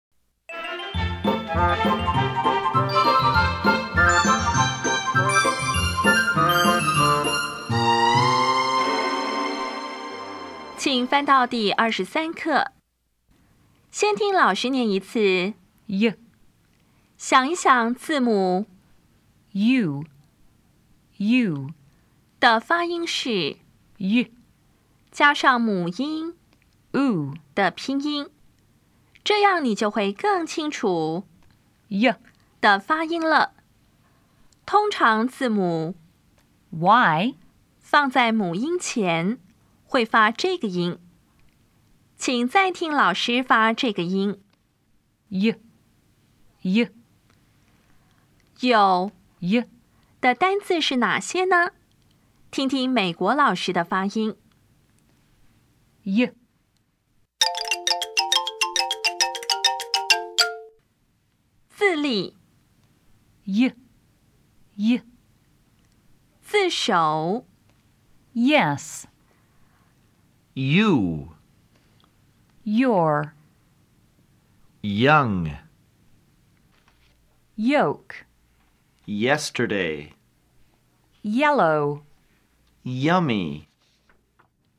当前位置：Home 英语教材 KK 音标发音 子音部分-2: 有声子音 [j]
音标讲解第二十三课
[jɛs]
[ju]
[ˋjɛstɚde]